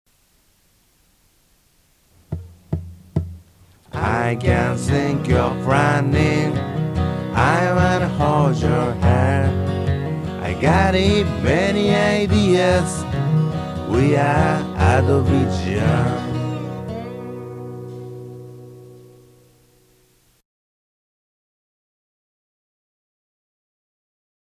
スライドギターでアメリカの雰囲気を感じさせるのに何度も録り直した記憶があります。